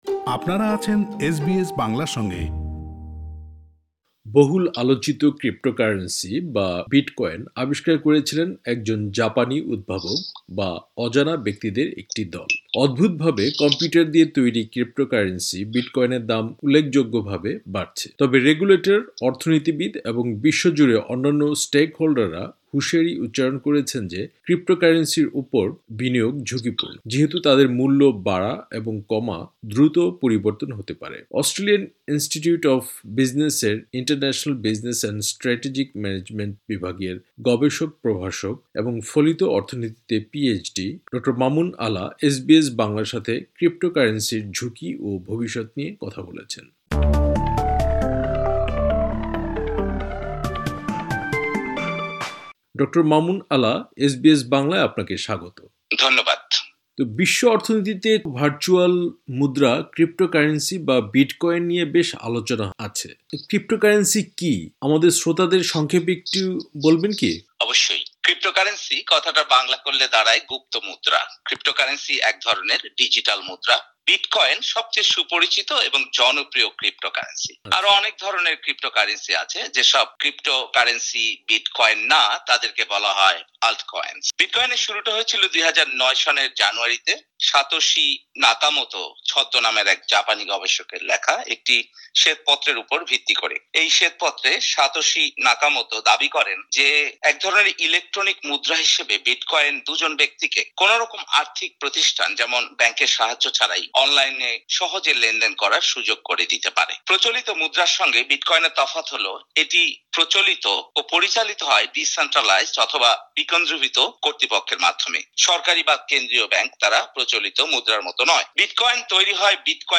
এসবিএস বাংলার সাথে ক্রিপ্টোকারেন্সির ঝুঁকি ও ভবিষ্যত নিয়ে কথা বলেছেন।